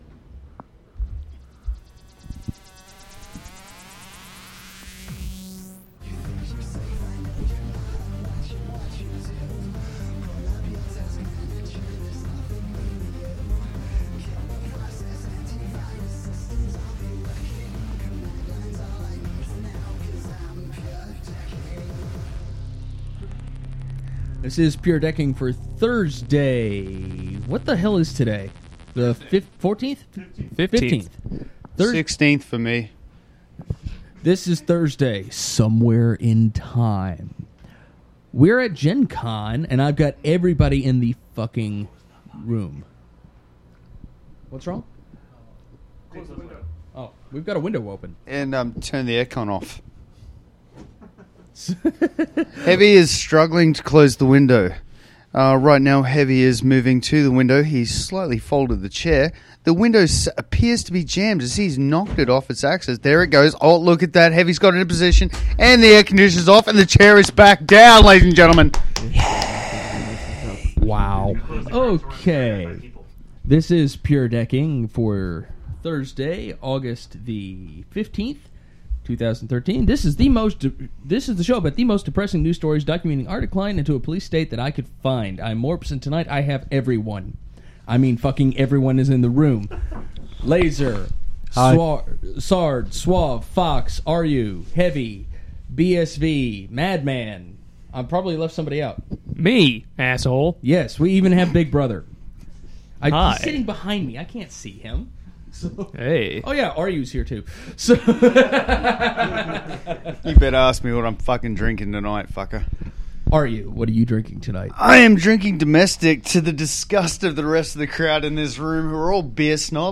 live at GenCon.